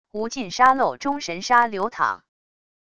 无尽沙漏中神沙流淌wav音频